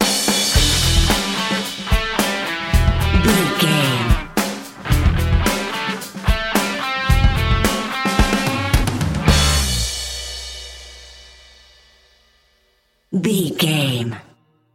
Epic / Action
Aeolian/Minor
drums
electric piano
electric guitar
bass guitar
Sports Rock
hard rock
angry
aggressive
energetic
intense
nu metal
alternative metal